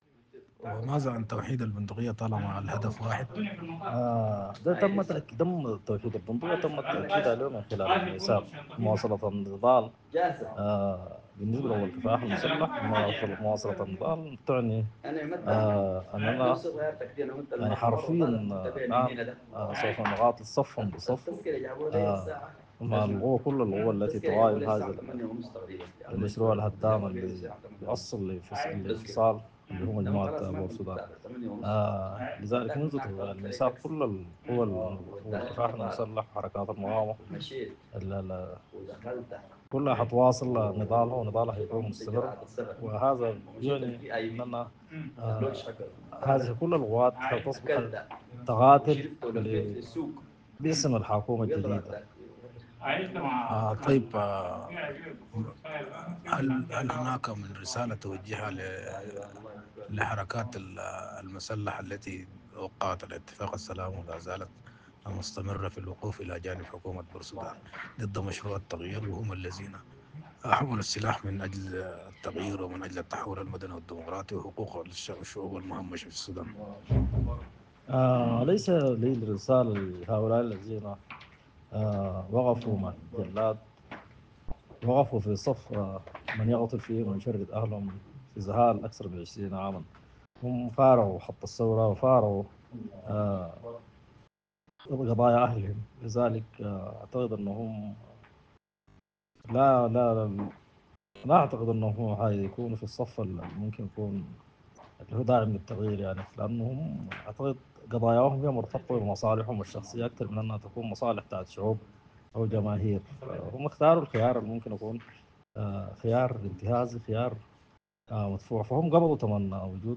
● على ضوء ذلك التقت “السودانية نيوز” بالأستاذ حافظ عبد النبي وزير الثروة الحيوانية السابق، واحد الموقعين على الميثاق التأسيسي، ليلقي الضوء على على الحدث التاريخي الهام